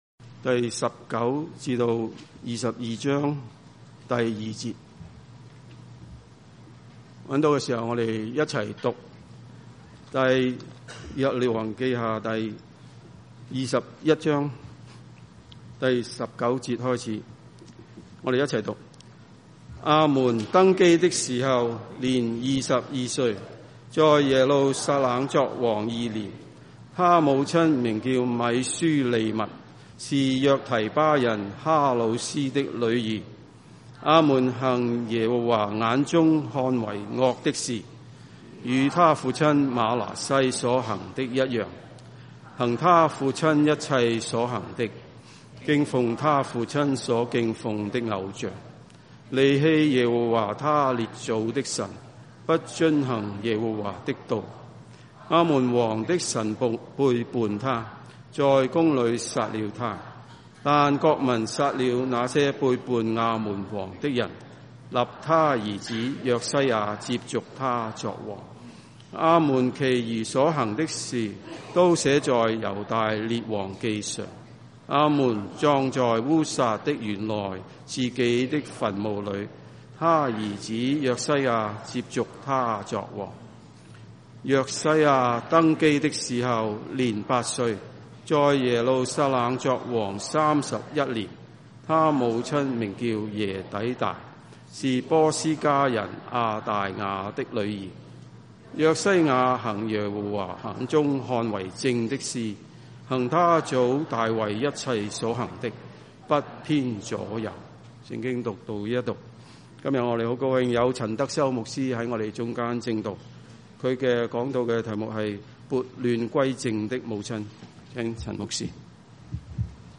華埠粵語二堂